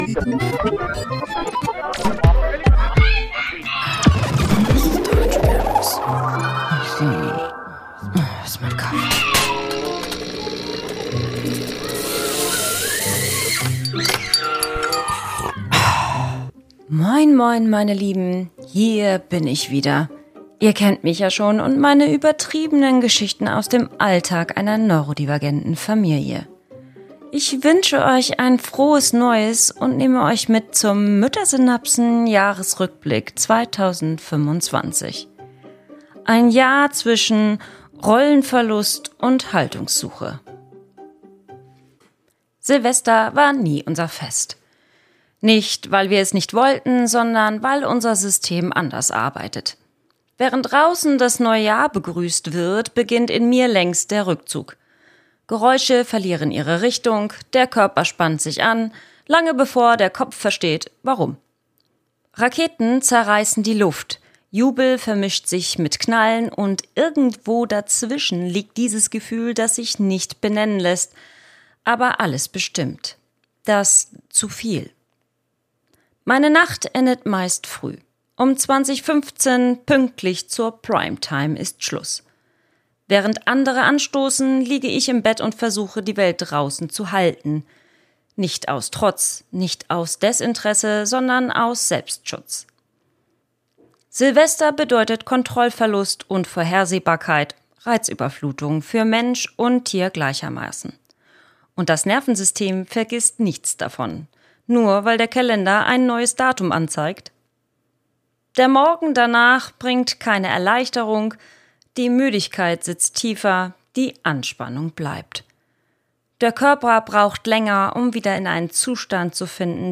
Ein ruhiger, erzählender Rückblick über ein Jahr zwischen Funktionieren und Innehalten. Über neurodivergentes Leben, Rollen, Erschöpfung und die Suche nach Haltung jenseits von Lösungen.